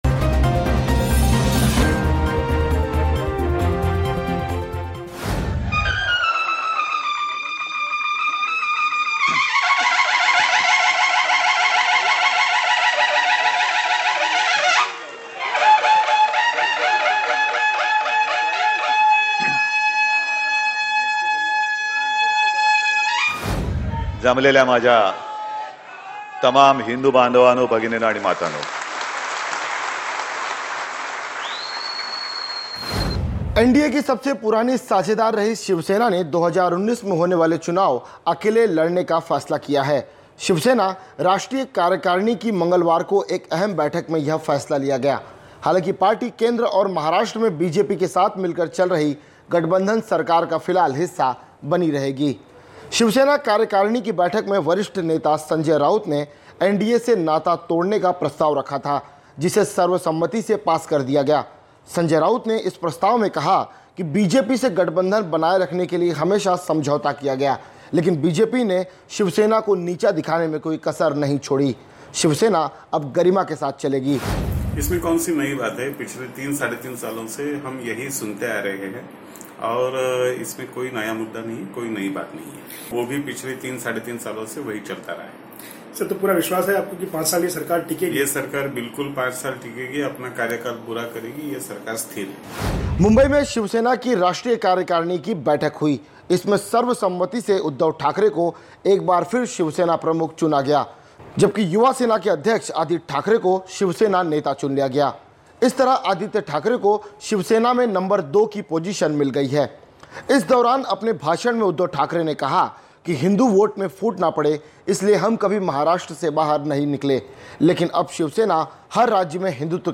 News Report / शिवसेना ने किया BJP से नाता तोड़ने का ऐलान, अकेले लड़ेगी 2019 का चुनाव